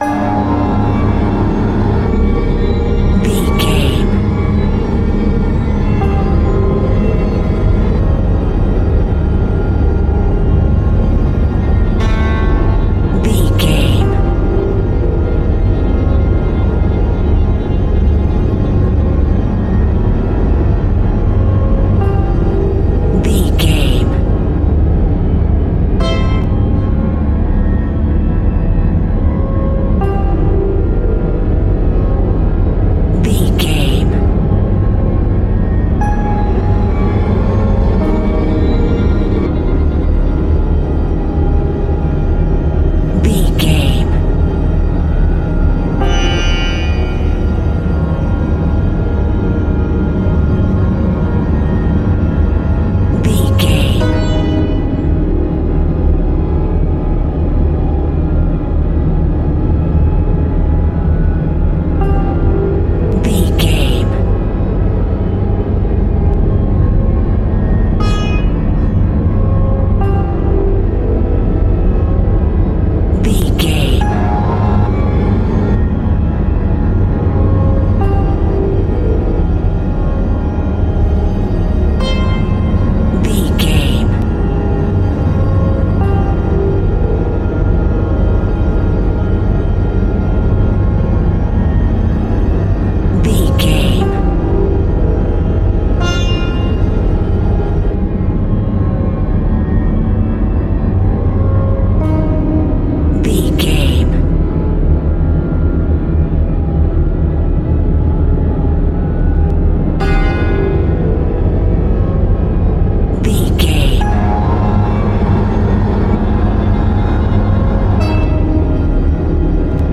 Sci Fi Music.
Atonal
ominous
haunting
eerie
Synth Pads
atmospheres